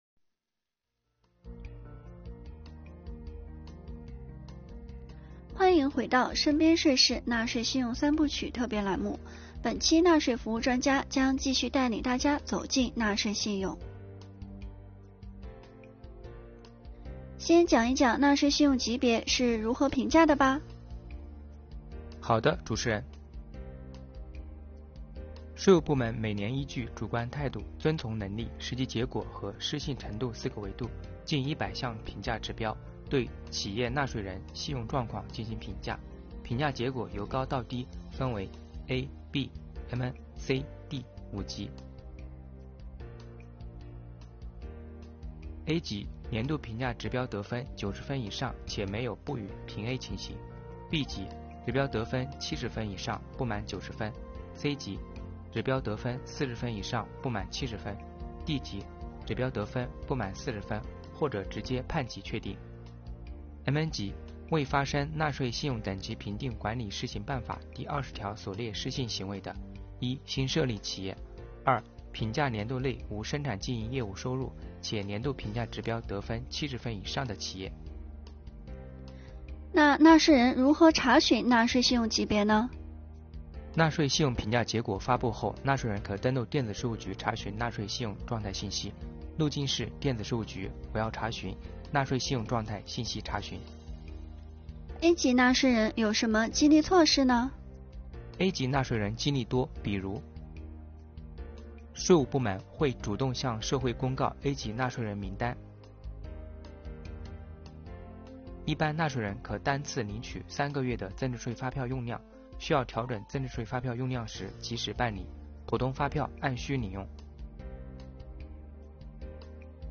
本期《身边税事》“纳税信用三部曲”专题栏目，纳税信用专家将带您走进纳税信用级别的那些事儿。